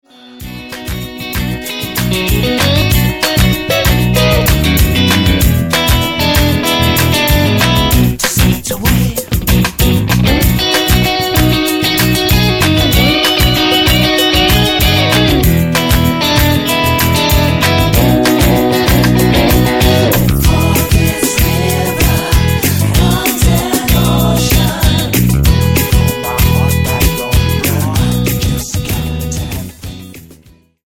Tonart:B mit Chor
Die besten Playbacks Instrumentals und Karaoke Versionen .